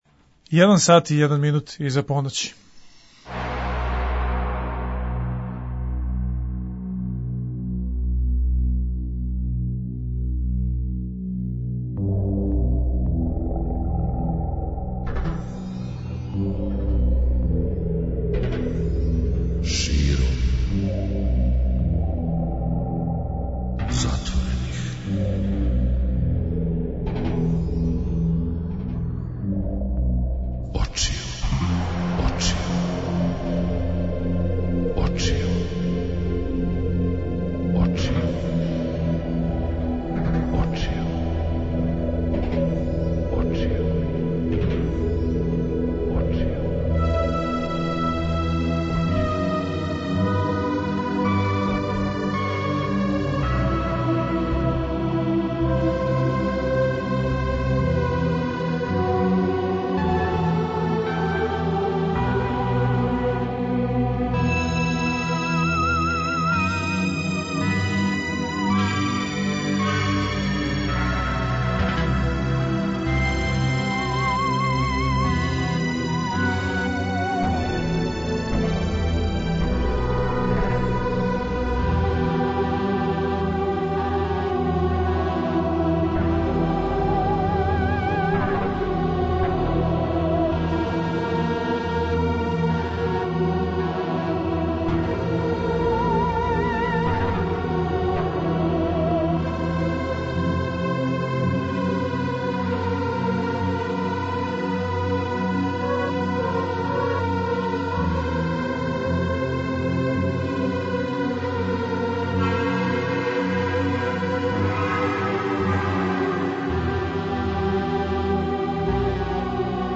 преузми : 42.55 MB Широм затворених очију Autor: Београд 202 Ноћни програм Београда 202 [ детаљније ] Све епизоде серијала Београд 202 Састанак наше радијске заједнице We care about disco!!!